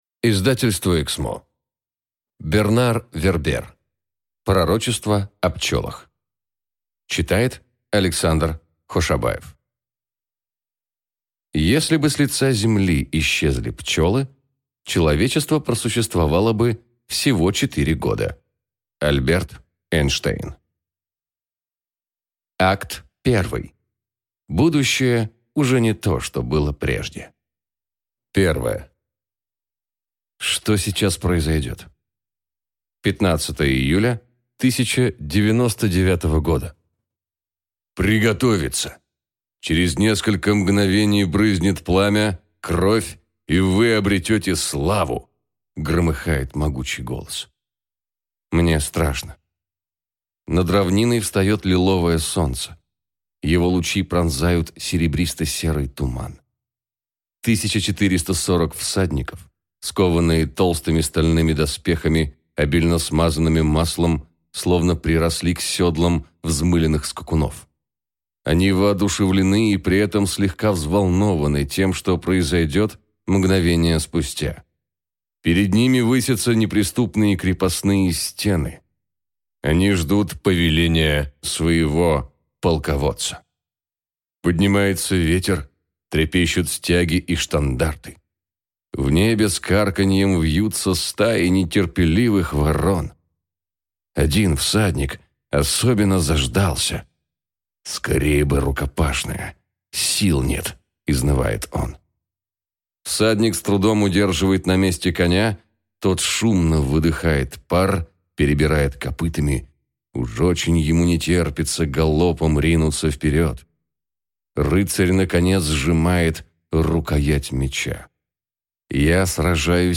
Аудиокнига Пророчество о пчелах | Библиотека аудиокниг